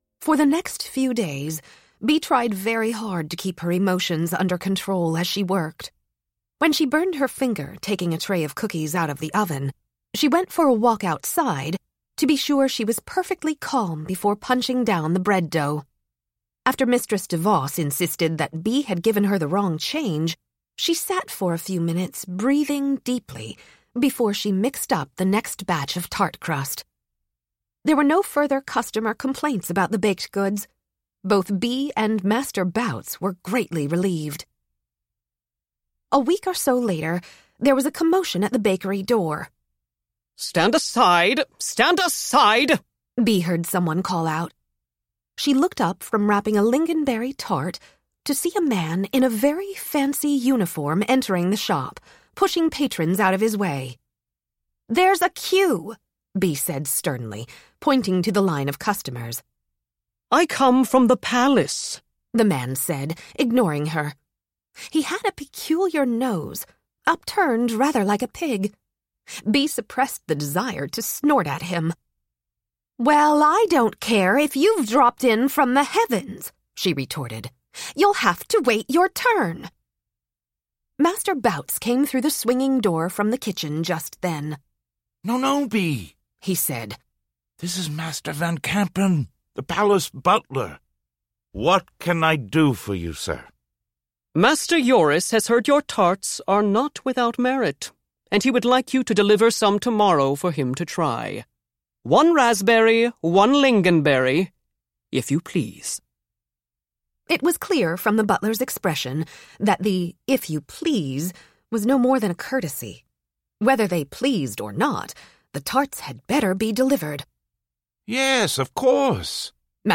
AudioFile Earphones Award " A full cast works together seamlessly in this charming magical adventure. ...The care in the production is evident..." AudioFile Magazine
Master Bout’s deep, gentle voice coaxes Bee out of her shell to reveal hidden magical talents.
The many narrators work together seamlessly to produce wholly believable characters and suffuse them with deep emotions. Chapters whiz by at high speed, capturing each development with passion and energy.
The care in the production is evident—the rest of the cast adds vocal personality to characters both large and small, and mood-appropriate snippets of music begin and end each chapter, helping to sweep listeners along.